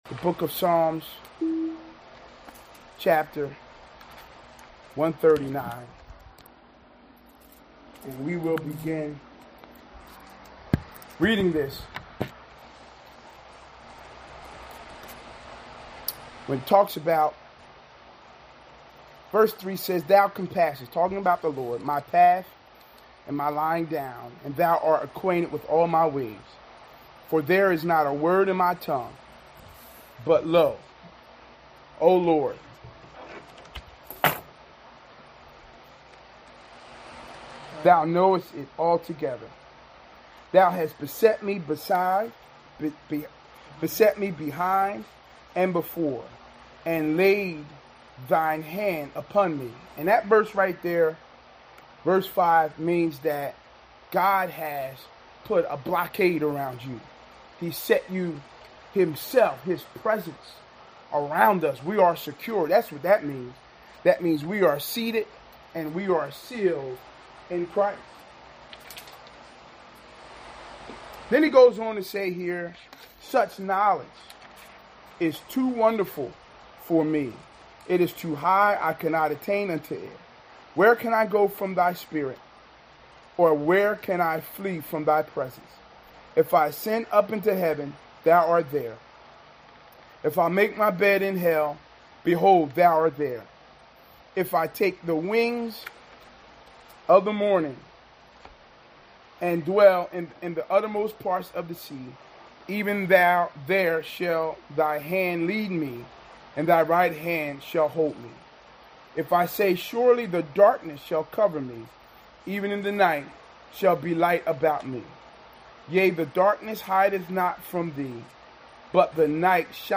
Sermons Sort By Date